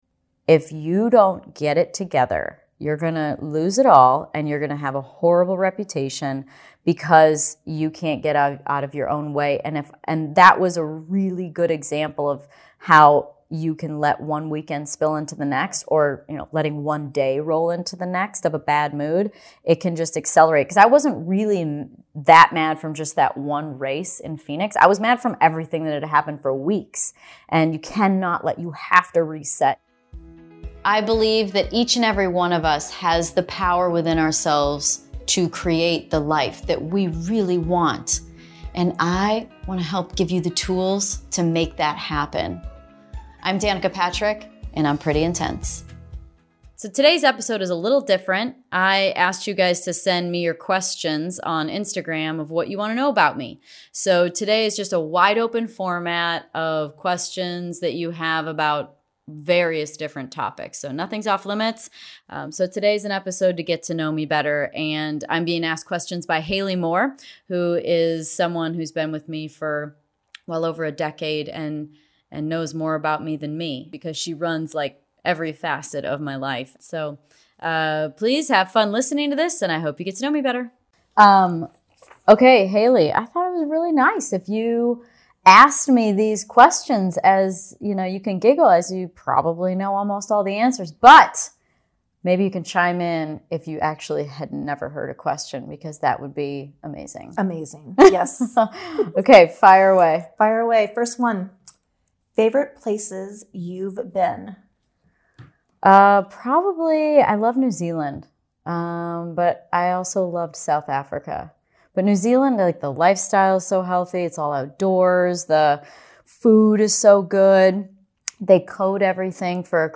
Today's show is a Q&A with Danica Patrick.